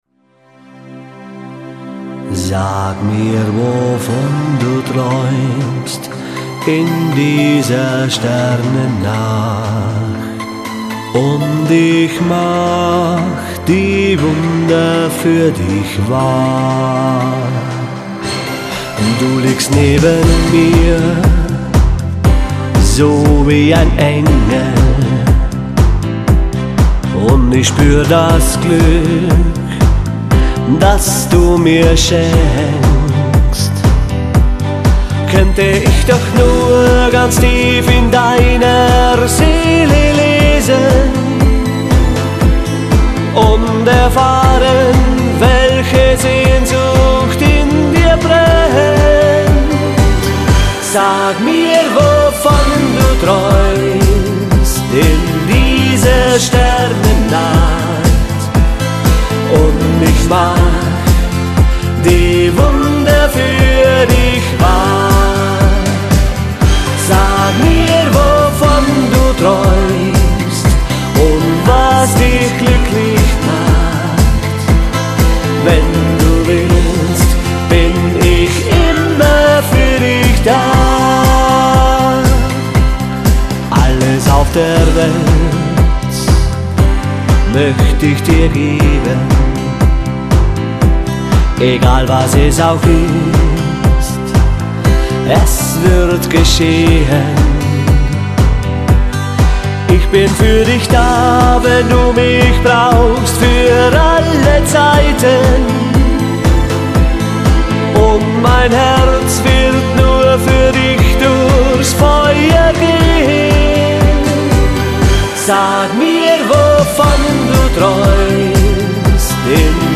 • Akkordeon
• Allround Partyband
• Duo/Trio/Quartett
• Volksmusik